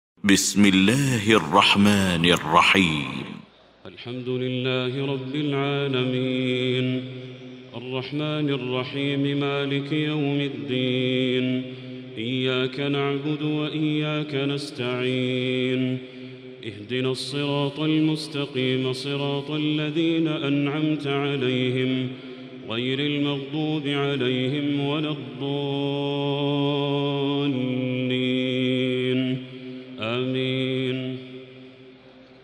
المكان: المسجد الحرام الشيخ: بدر التركي بدر التركي الفاتحة